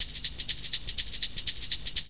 TAMBOU 1  -R.wav